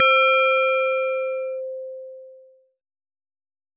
Fm bell synth 02
bell bells ding doorbell FM percussion ring synth sound effect free sound royalty free Sound Effects